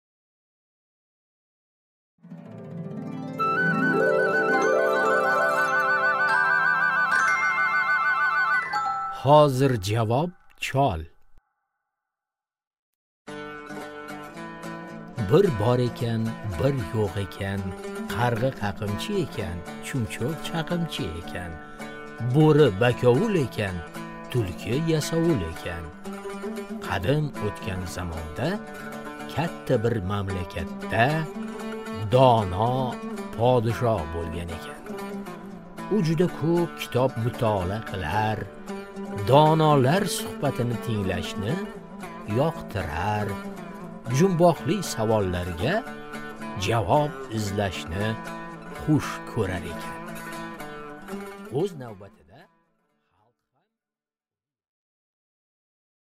Аудиокнига Hozirjavob chol